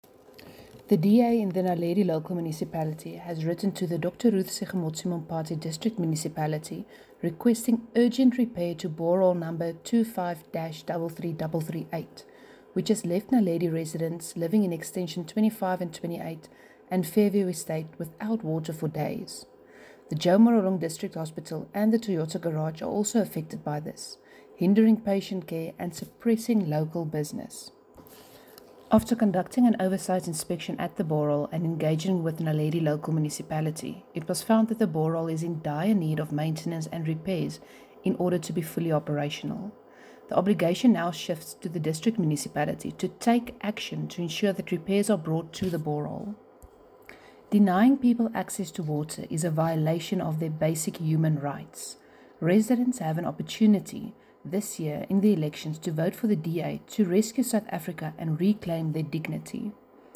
Issued by Cllr Hendriëtte van Huyssteen – DA Caucus Leader, Naledi Local Municipality
Note to Broadcasters: Please find linked soundbites in